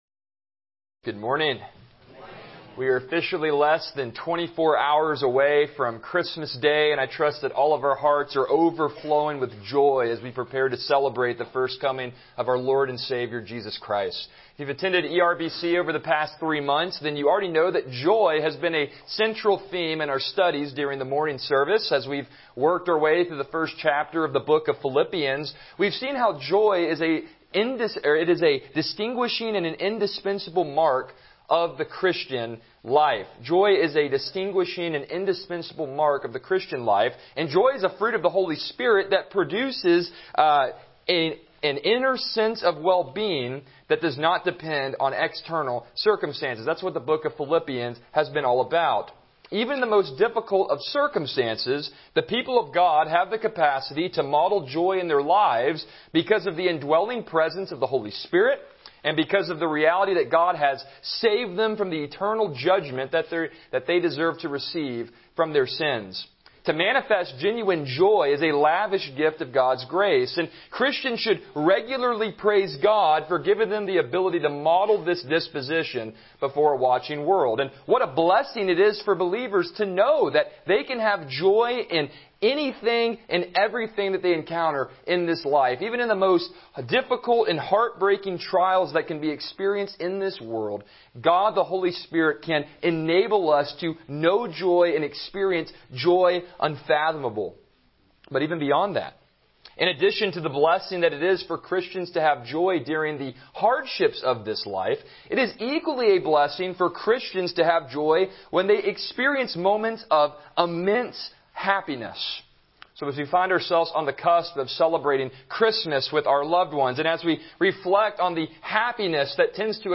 Passage: Luke 1:46-55 Service Type: Morning Worship « Chapter 1.8